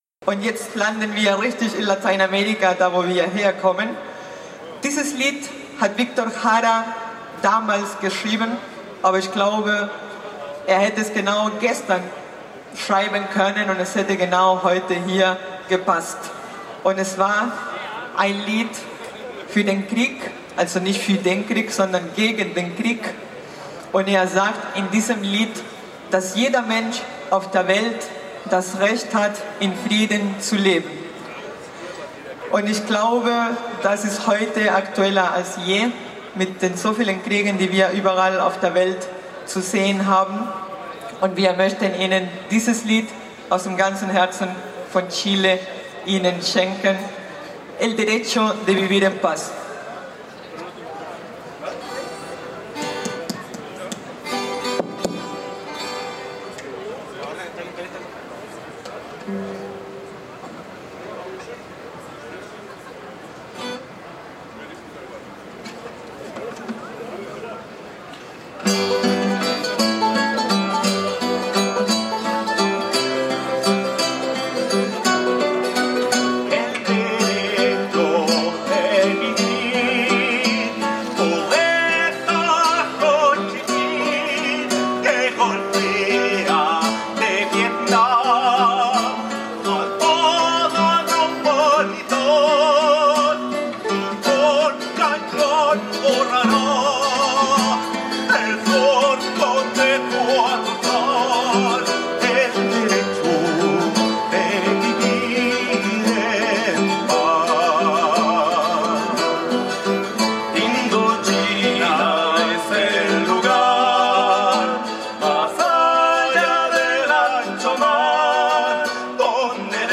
Eindrücke der lateinamerikanisch/europäisch geprägten musikalischen Darbietung von „Musikandes“[7, 8] auf dem Marktplatz[9] in der Düsseldorfer Altstadt.
Die Kundgebung wurde musikalisch untermalt von „Musikandes“